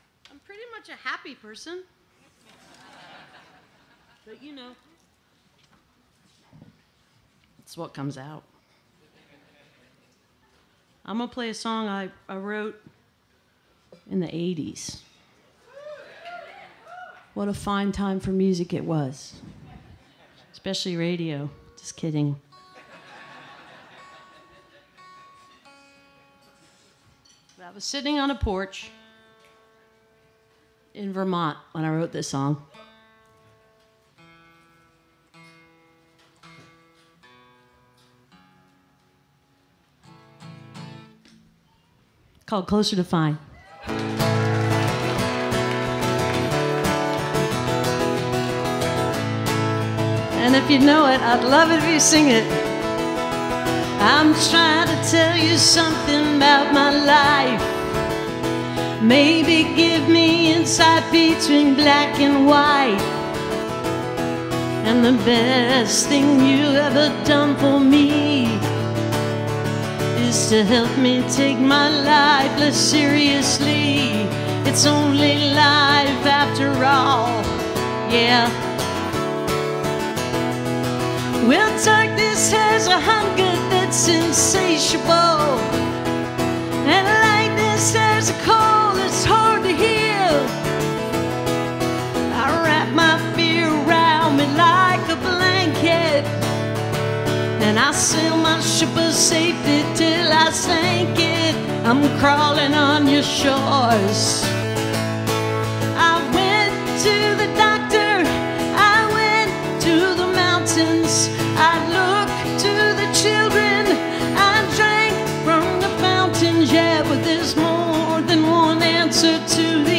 (captured from an online radio broadcast)